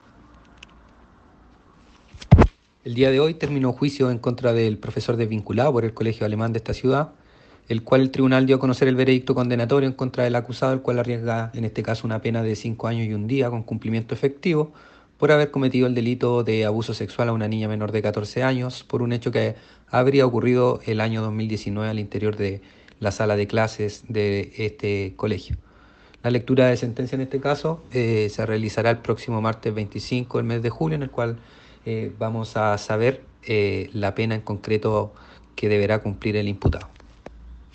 Fiscal Subrogante Alejandro López …